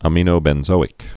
(ə-mēnō-bĕn-zōĭk, ămə-)